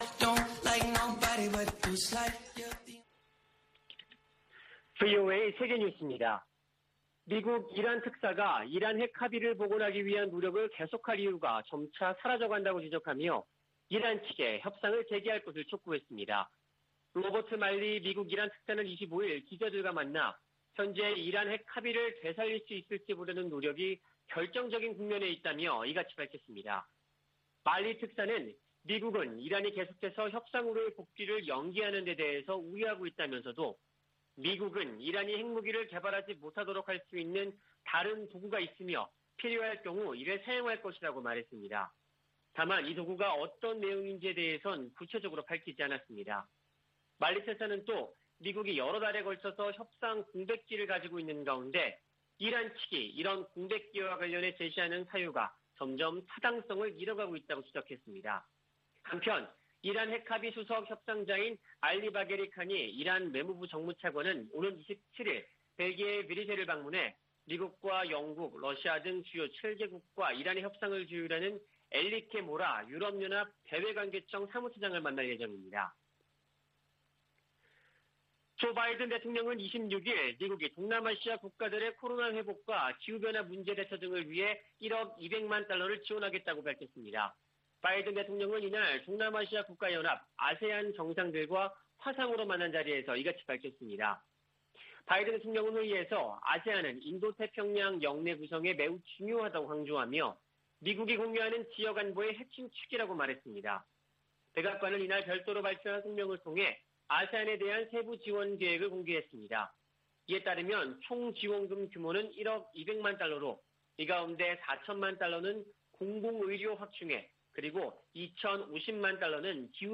VOA 한국어 아침 뉴스 프로그램 '워싱턴 뉴스 광장' 2021년 10월 27일 방송입니다. 조 바이든 미국 행정부는 대북정책 기조를 발표한 지 약 6개월이 지난 현재, 북한의 미사일 발사에 단호한 입장을 밝히면서도 한국 등과 대북 관여 방안을 모색하고 있습니다. 미 재무부가 최근 '인도주의 지원에 대한 영향 최소화' 등을 골자로 제재 검토 보고서를 낸데 관해 전문가들은 대북 제재 관련 실질적인 변화 가능성에 회의적인 견해를 밝혔습니다. 미국과 한국이 대북 인도적 지원을 위한 구체적 방안을 검토 중인 가운데 민간 구호단체들도 활동 재개를 준비하고 있습니다.